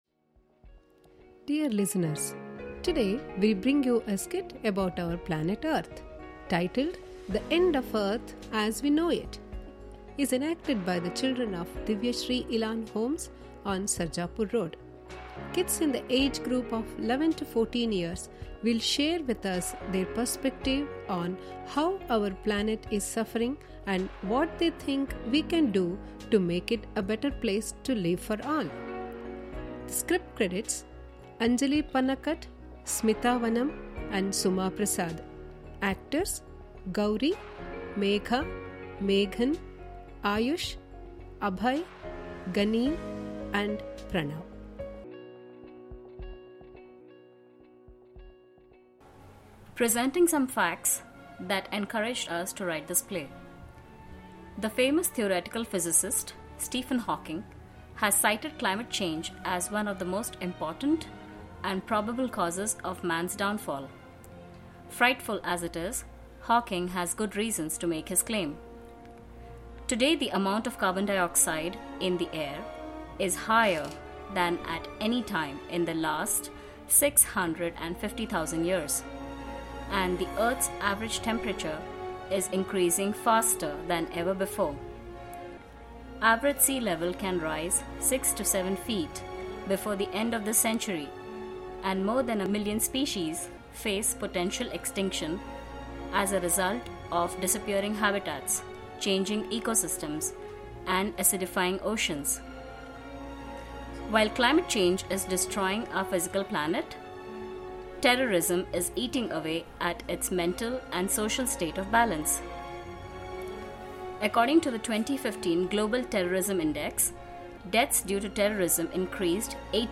Elan_Kids_Radio_Recording.mp3